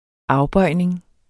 Udtale [ ˈɑwˌbʌjˀneŋ ]